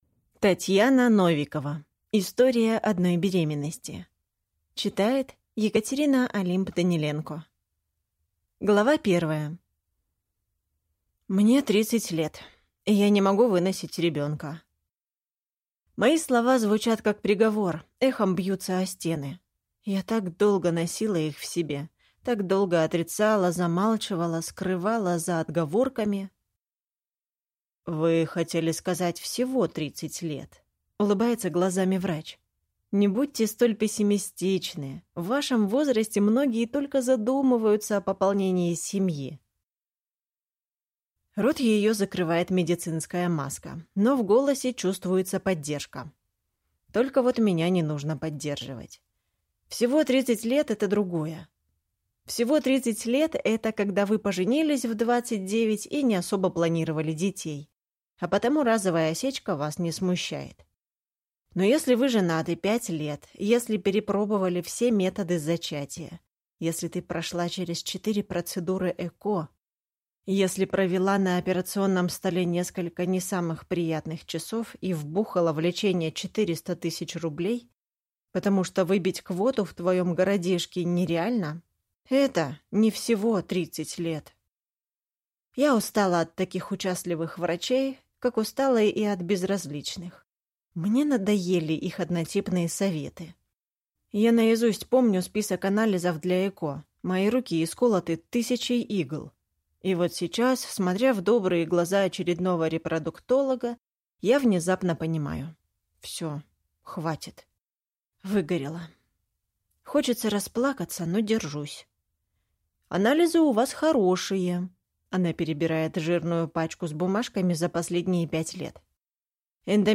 Аудиокнига История одной беременности | Библиотека аудиокниг
Прослушать и бесплатно скачать фрагмент аудиокниги